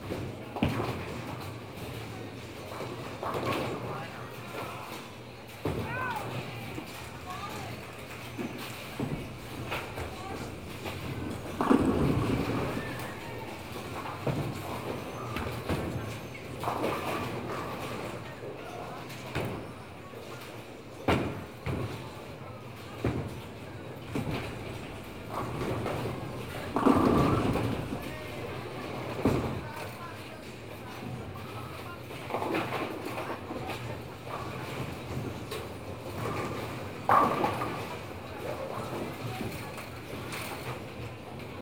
bowling.ogg